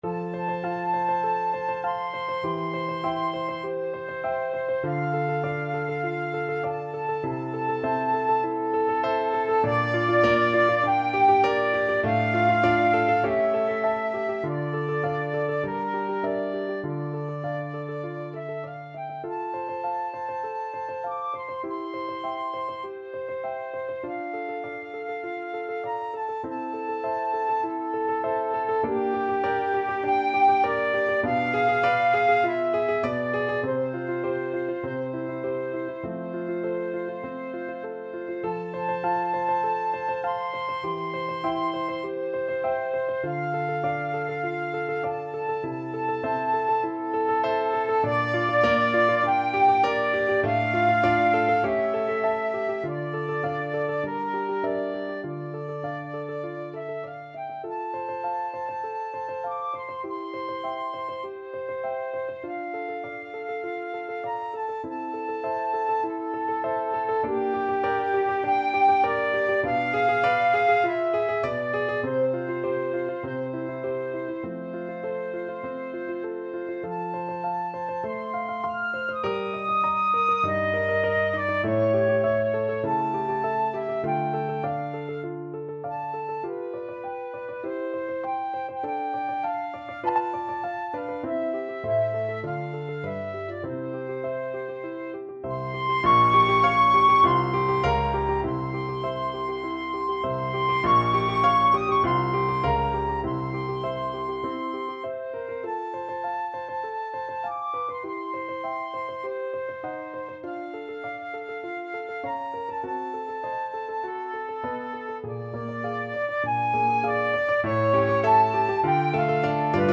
F major (Sounding Pitch) (View more F major Music for Flute )
4/4 (View more 4/4 Music)
Allegro moderato (View more music marked Allegro)
Flute  (View more Intermediate Flute Music)
Classical (View more Classical Flute Music)